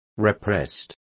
Complete with pronunciation of the translation of repressed.